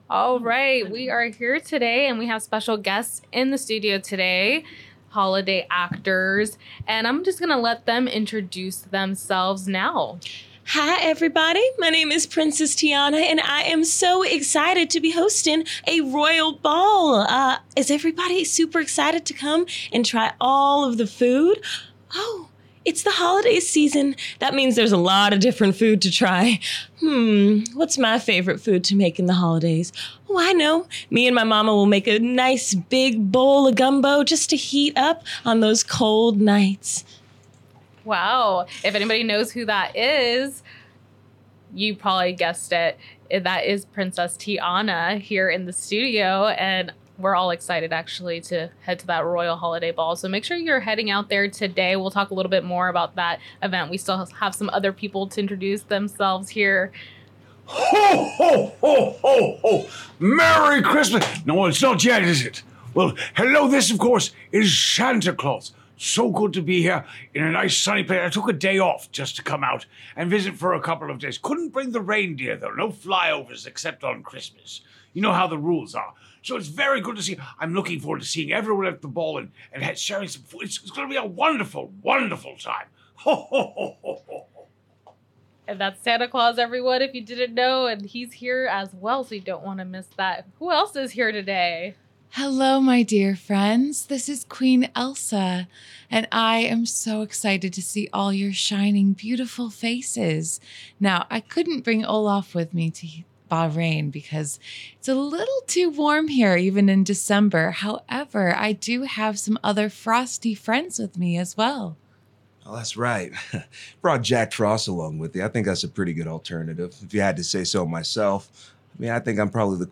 This week’s Interview with Armed Forces Entertainment Holiday For The Heroes characters on tour featured discussions about their performance and dance sing alongs for the upcoming Royal Holiday Ball event on base.